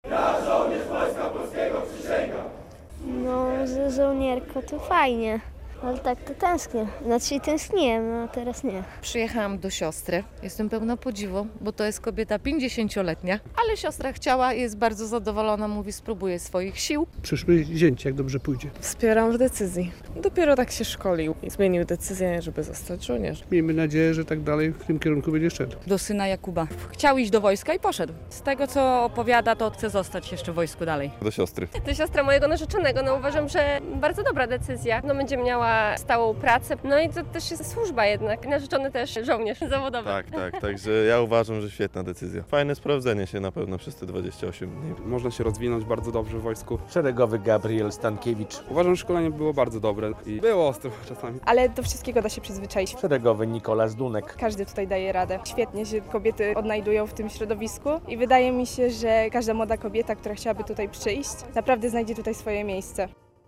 Osiemdziesięciu siedmiu żołnierzy po czterotygodniowym przeszkoleniu złożyło na Starym Rynku w Łomży przysięgę wojskową na sztandar 18. Łomżyńskiego Pułku Logistycznego.
relacja